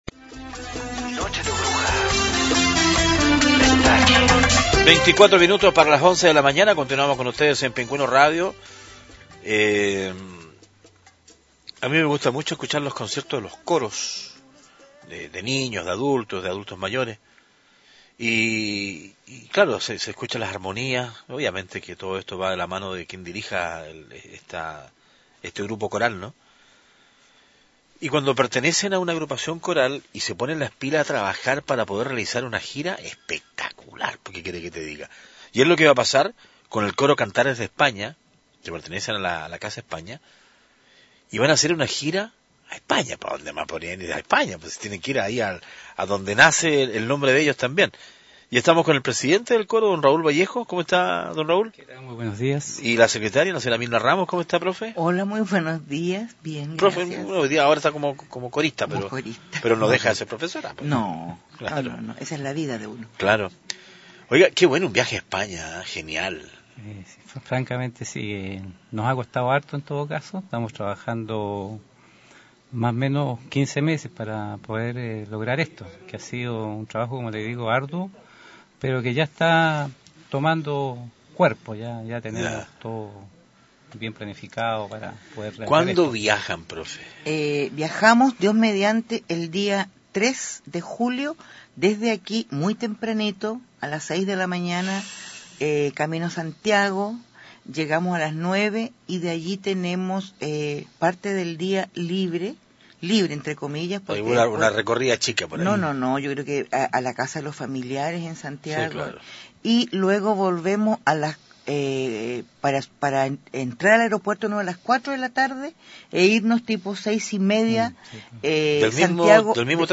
Entrevistas de Pingüino Radio - Diario El Pingüino - Punta Arenas, Chile